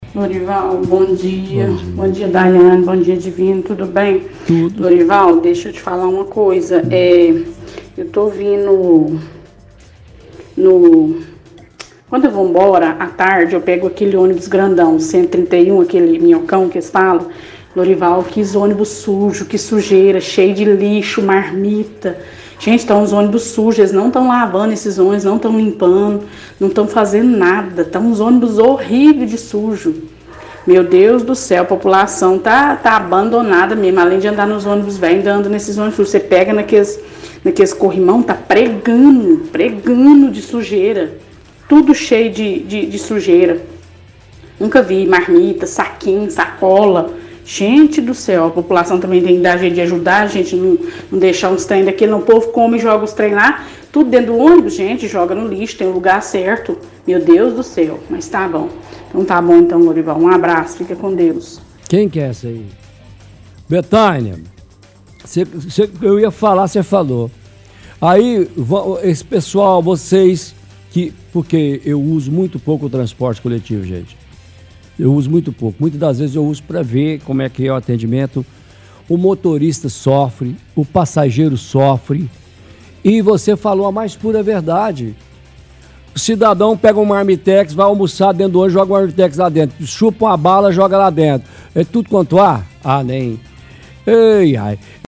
– Ouvinte reclama de sujeira dos ônibus e diz que a população está abandonada.